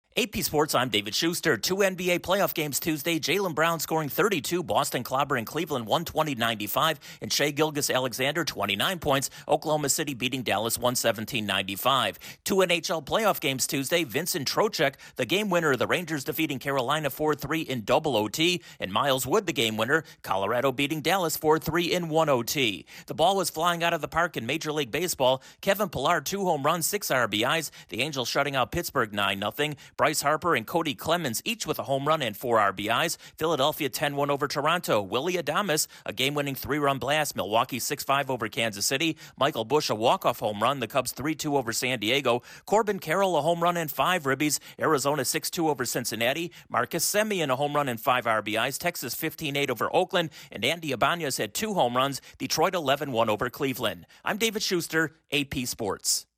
Sports News from the Associated Press / The latest in sports
Two NBA and two NHL playoff games were contested Tuesday night and Major League Baseball offered huge offensive performances. Correspondent